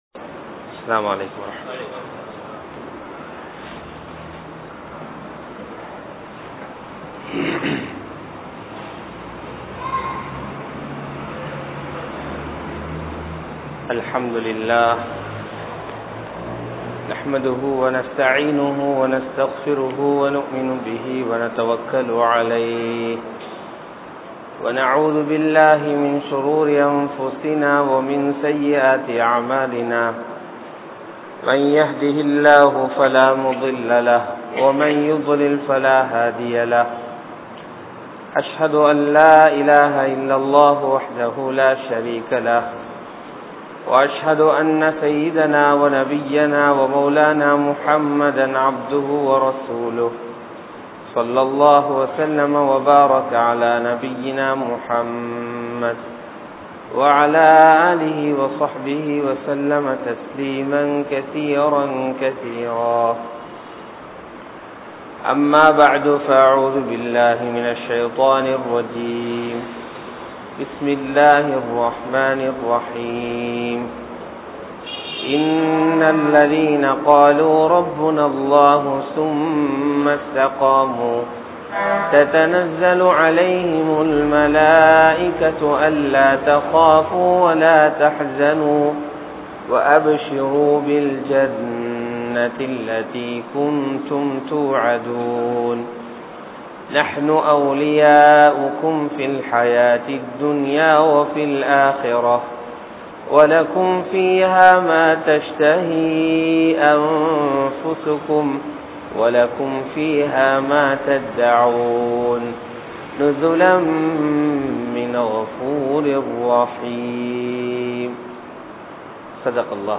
Allah`vin Uthavi Veanduma?? | Audio Bayans | All Ceylon Muslim Youth Community | Addalaichenai